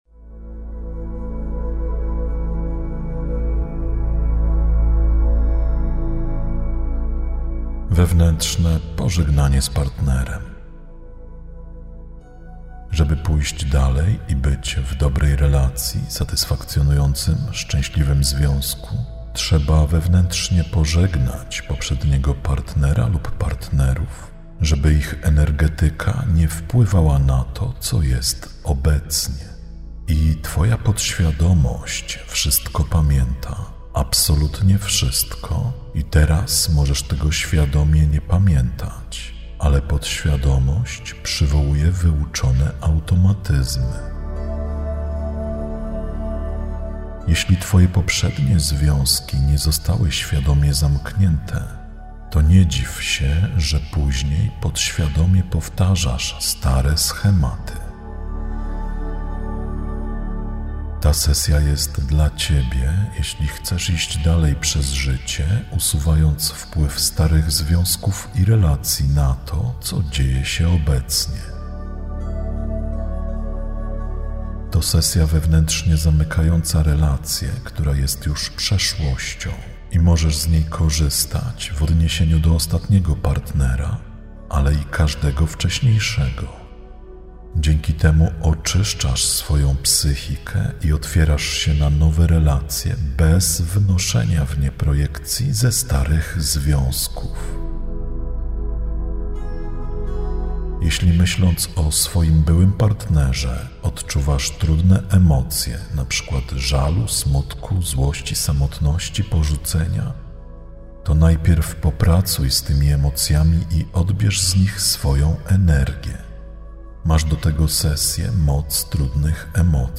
Zawiera lektora: Tak